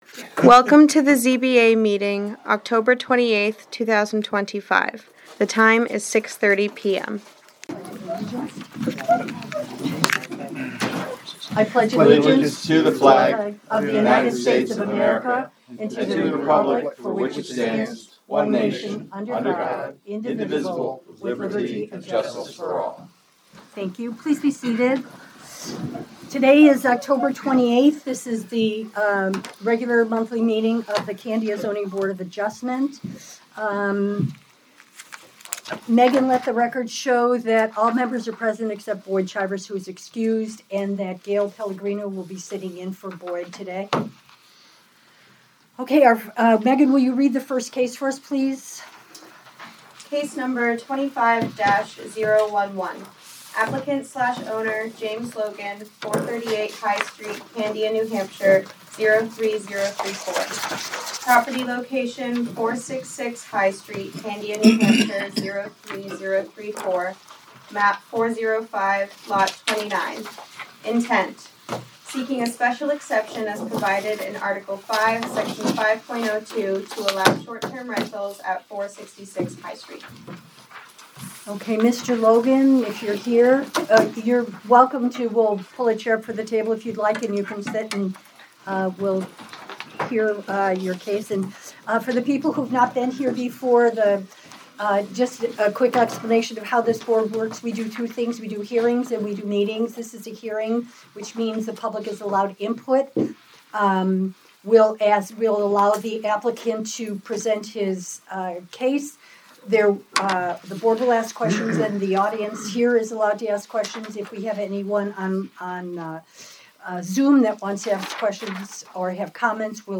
Audio recordings of committee and board meetings.
Zoning Board of Adjustment Meeting